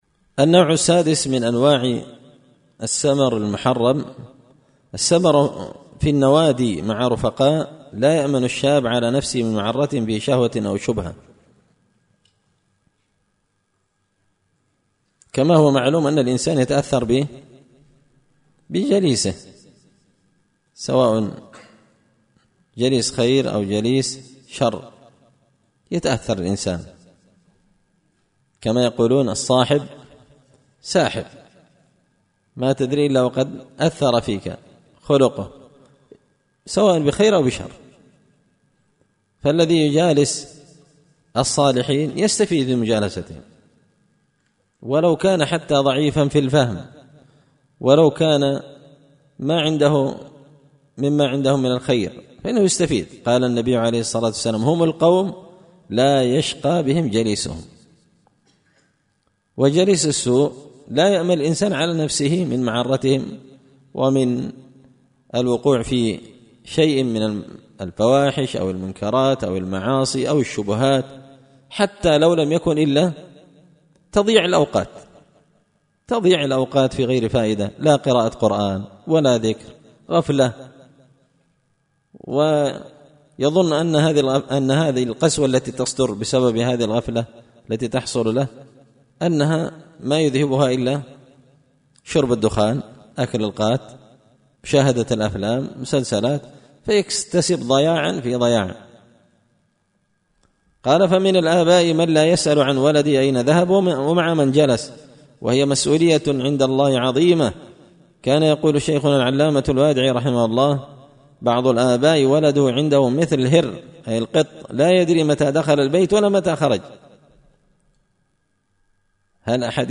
إمتاع النظر بأحكام السمر والسهر ـ الدرس التاسع عشر